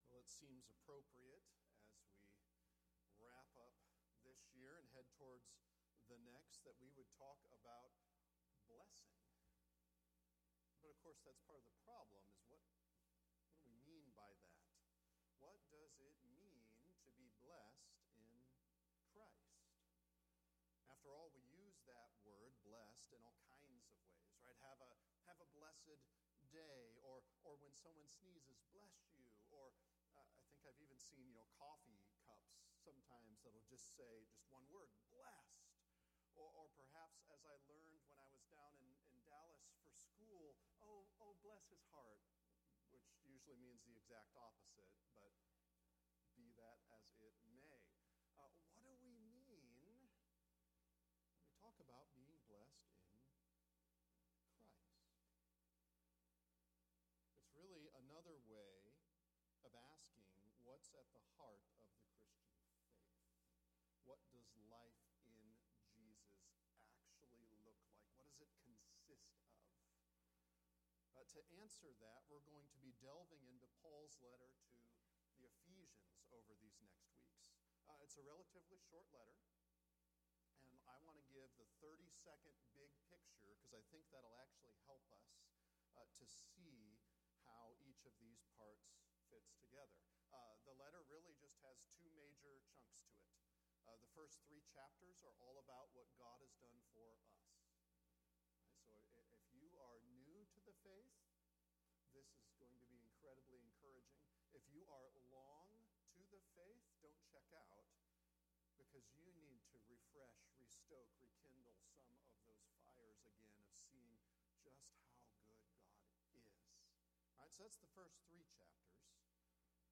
Ephesians 1:1-10 Blessed in Christ – Sermons